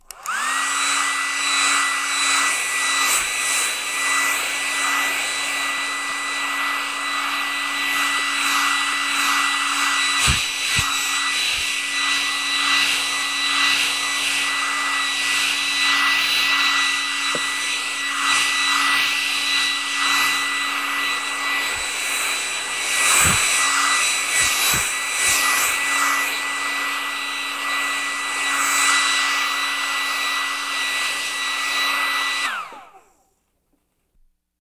hairdry.ogg